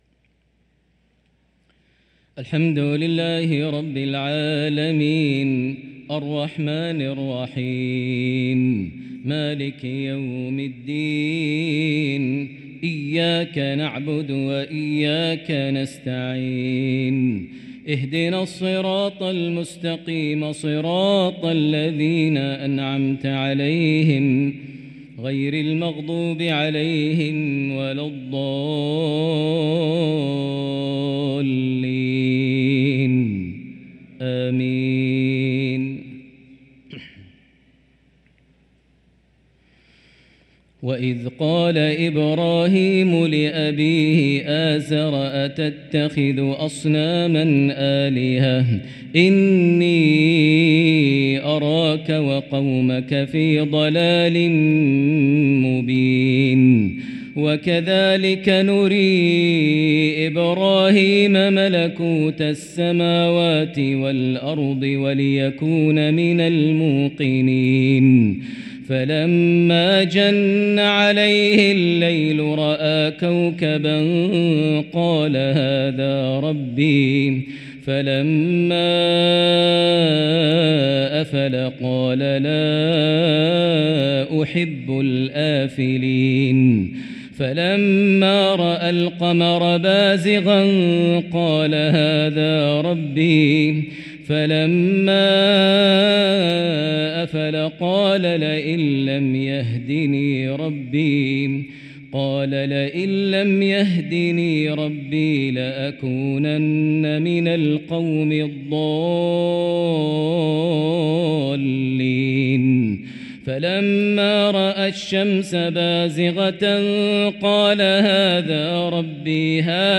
صلاة العشاء للقارئ ماهر المعيقلي 30 ربيع الآخر 1445 هـ
تِلَاوَات الْحَرَمَيْن .